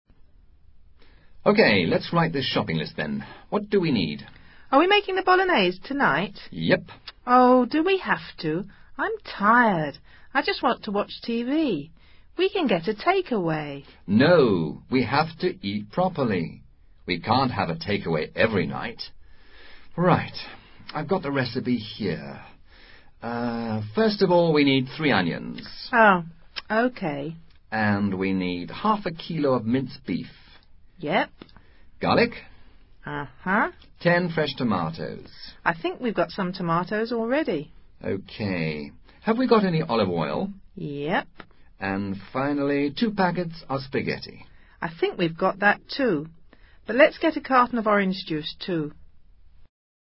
Diálogo en el que, a través de una receta, se incorporan elementos léxicos relacionados con la comida y la cocina.